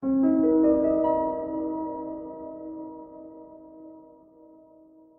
feat: add hyprland shutdown sound and login audio greeting;
login.ogg